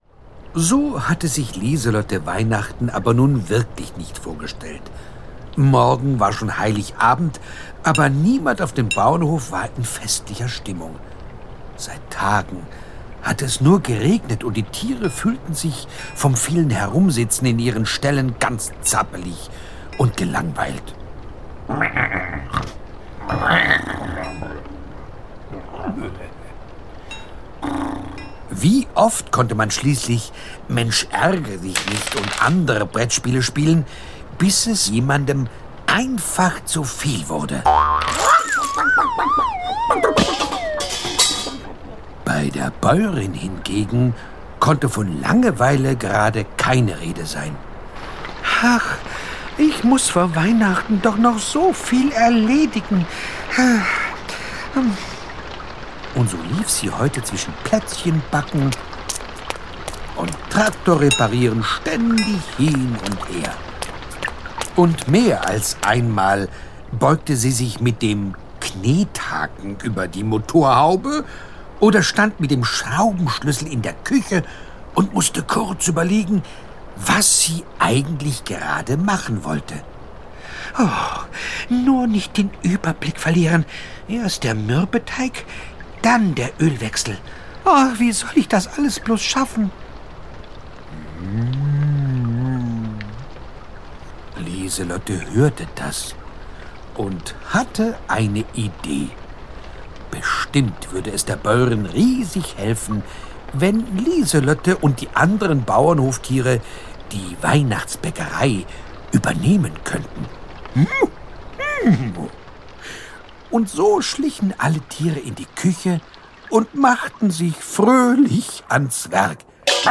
Die schönsten Geschichten zum Fest | Hörbuch über die Adventszeit für Kinder ab 3 Jahren
Gekürzt Autorisierte, d.h. von Autor:innen und / oder Verlagen freigegebene, bearbeitete Fassung.